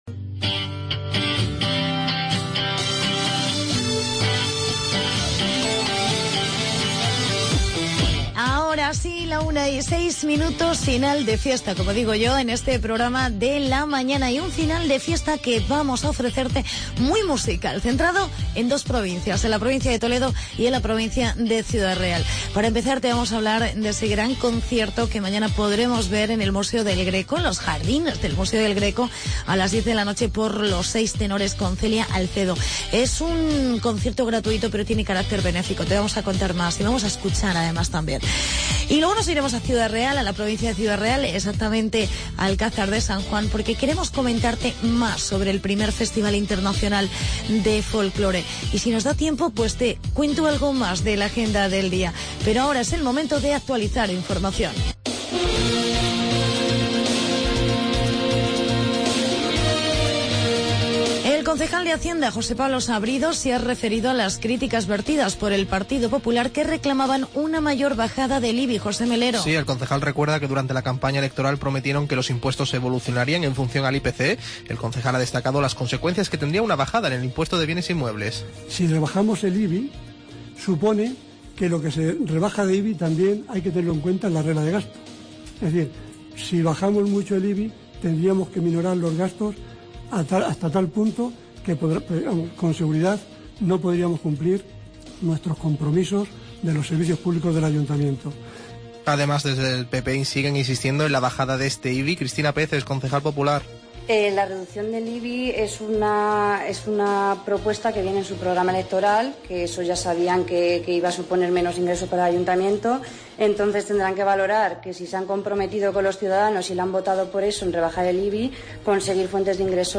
Entrevistamos